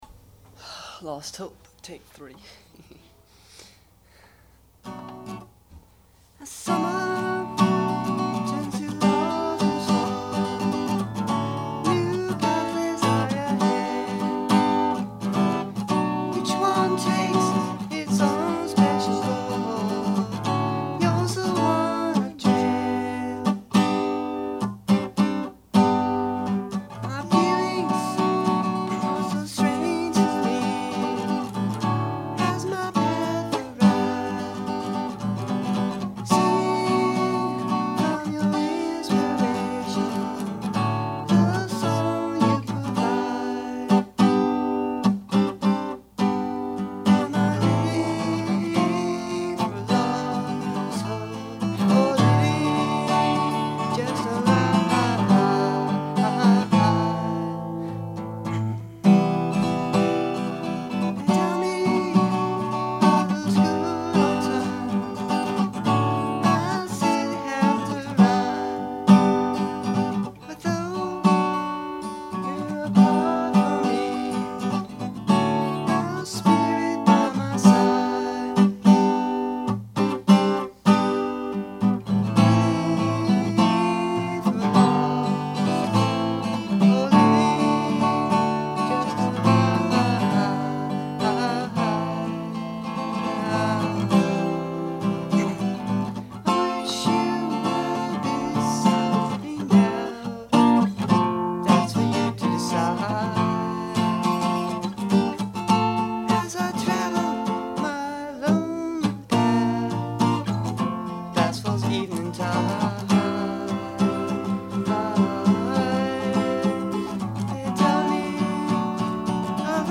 recorded the tracks on the reel-to-reel tape separately from the rehearsal
as a demo over the Summer of 1978.
Gibson Les Paul guitar, vocals